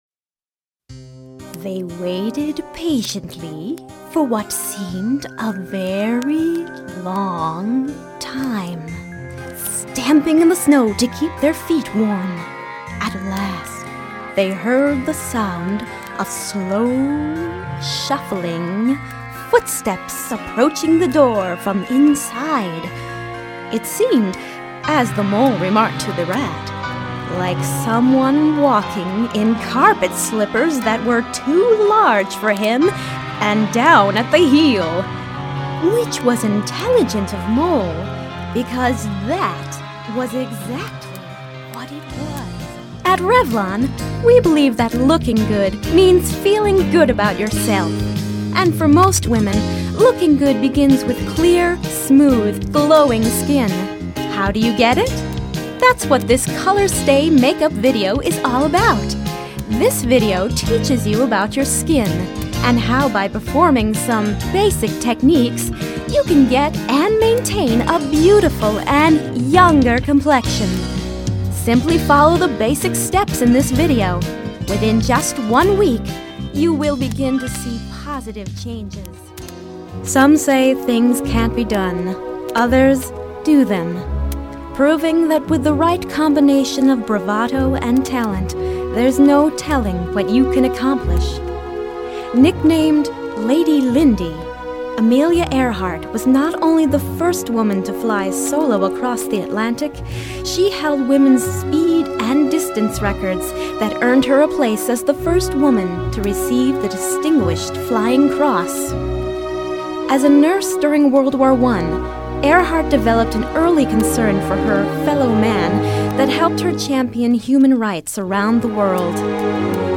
Voice Over Demo